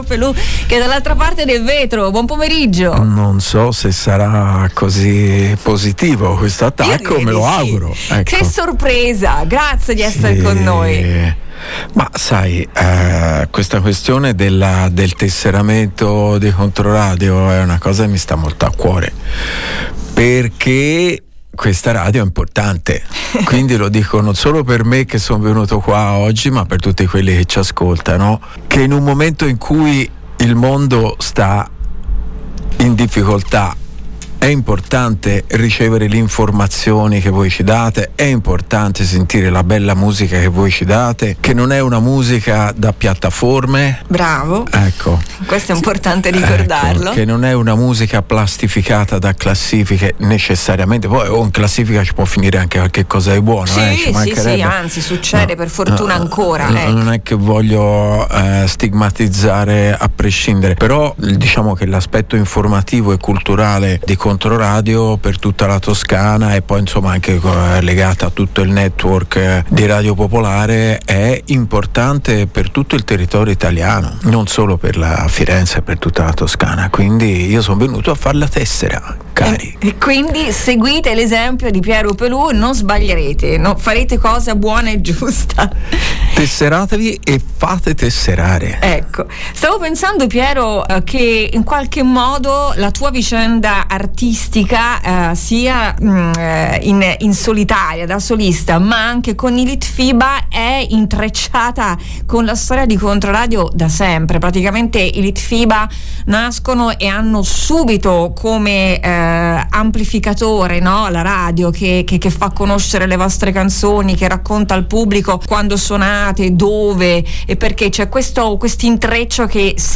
Con Piero Pelù nei nostri studi abbiamo parlato del tour di “17 Re”, di S.O.S. Palestina, della scomparsa di Giancarlo Cauteuccio e della necessità di sostenere il Controradio Club: “FRUGATEVI!!!”.